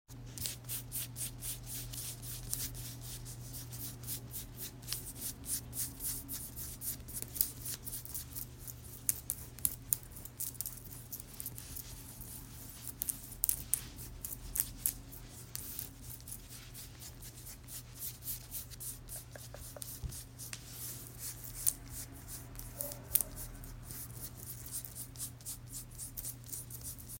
Aggressive skin scratching - is